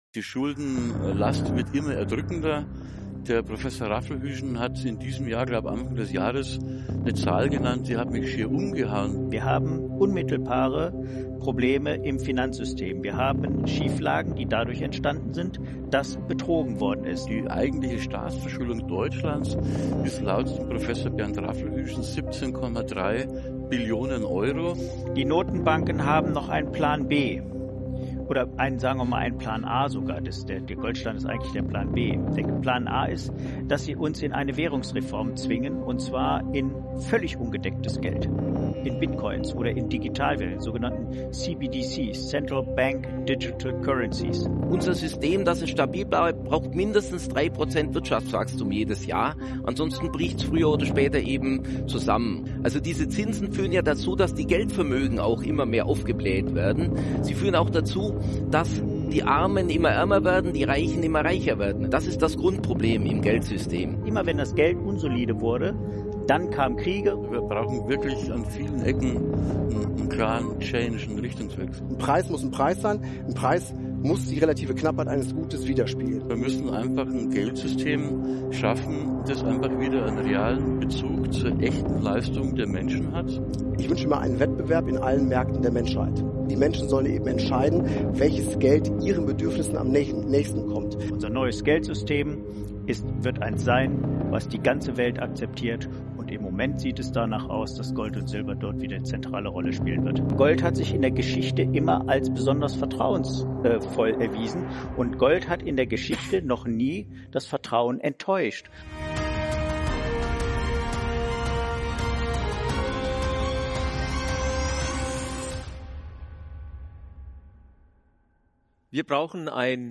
Beschreibung vor 2 Monaten Der Zusammenprall unterschiedlicher wirtschaftlicher Denkschulen prägt dieses Wirtschafts-Panel: Kritik an Schuldenlogik, Zweifel an zentraler Geldmacht und die Suche nach tragfähigen Alternativen bieten Raum für Kontroversen.